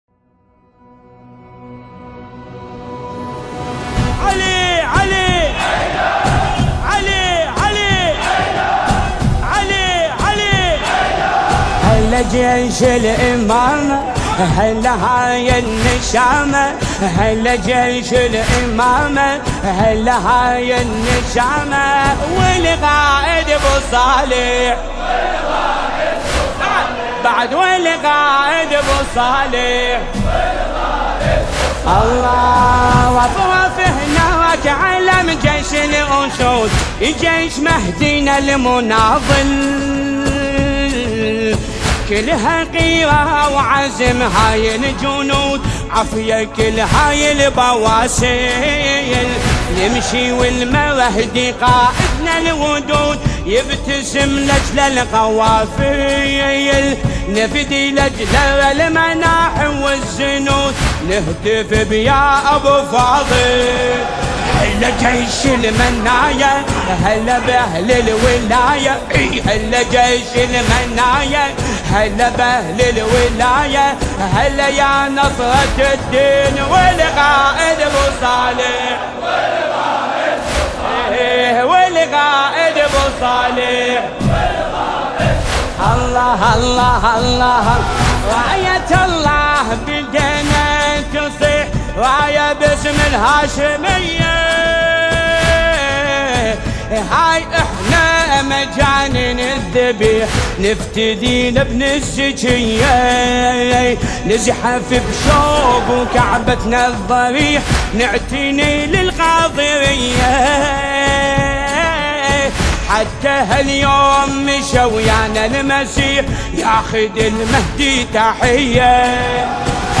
نماهنگ دلنشین عربی